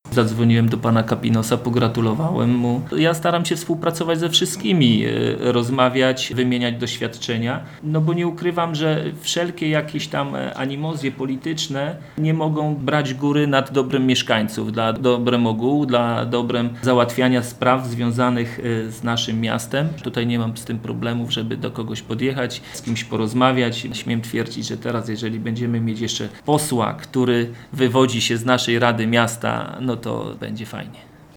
Staram się współpracować ze wszystkimi i animozje nie mogą brać górę… tak mówi prezydent Mielca Jacek Wiśniewski po tym jak wiceprzewodniczący rady miejskiej w Mielcu Fryderyk Kapinos został wybrany na posła w tegorocznych wyborach parlamentarnych do Sejmu. To dla miasta Mielca duże wyróżnienie, dodaje prezydent Mielca.